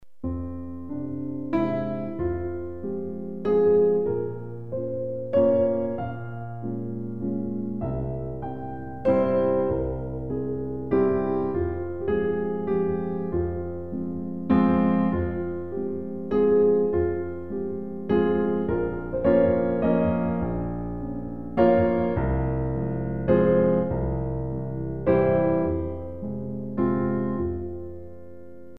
All piano CD for Pre- Ballet classes.
3/4 Accent 3